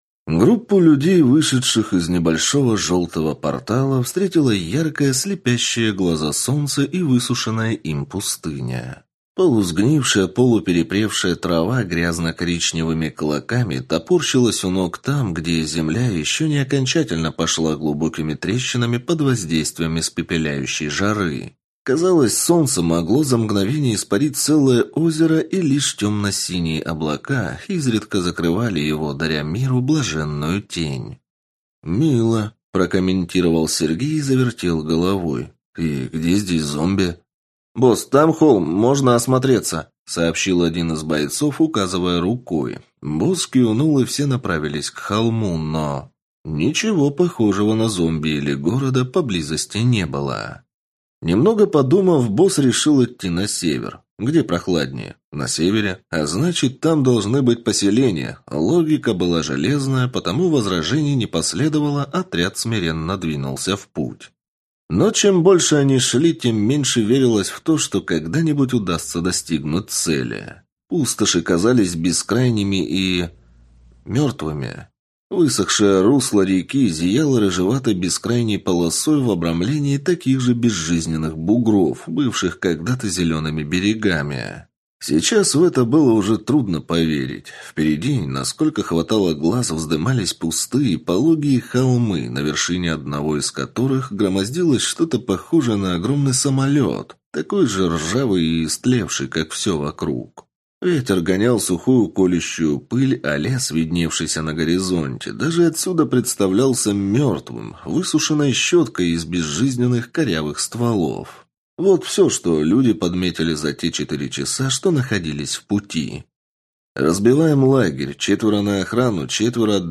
Аудиокнига Меж двух миров | Библиотека аудиокниг